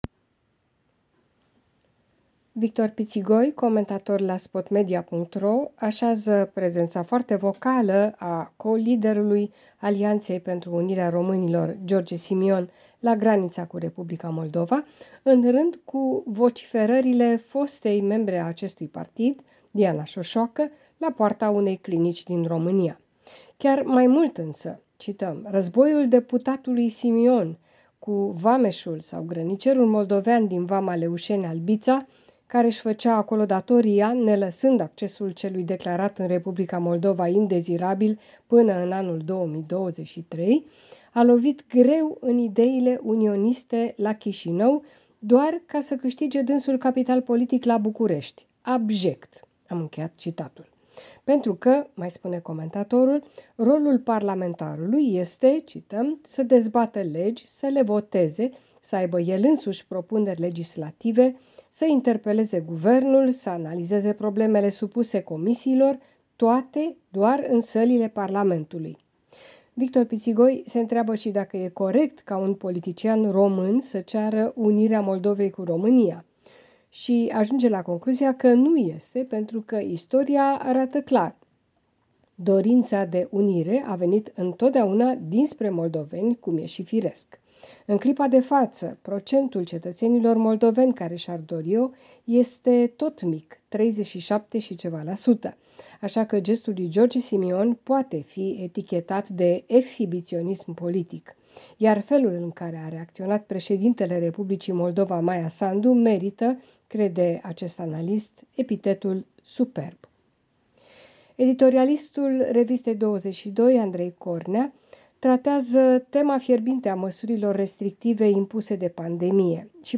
Revista presei de la București.